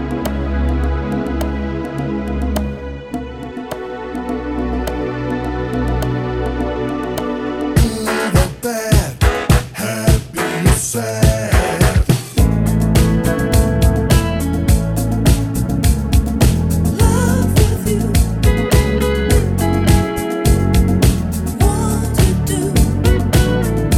Two Semitones Down Pop (1980s) 3:53 Buy £1.50